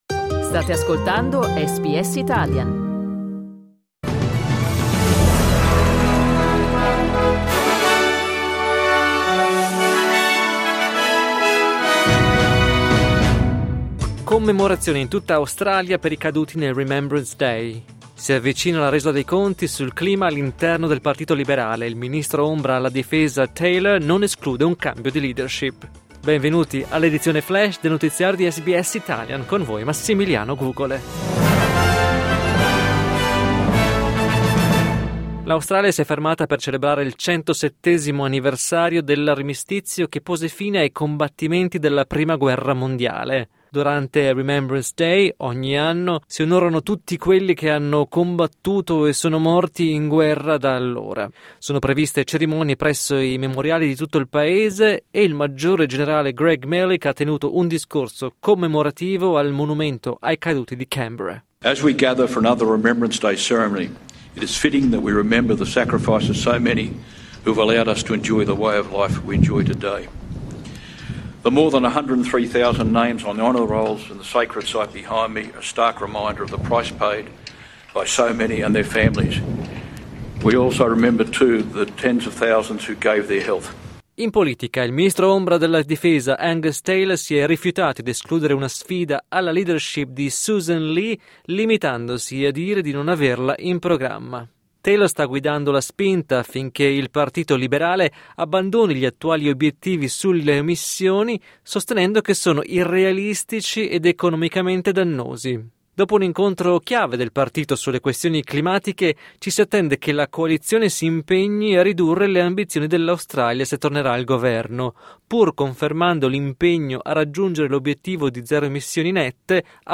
News flash martedì 11 novembre 2025
L’aggiornamento delle notizie di SBS Italian.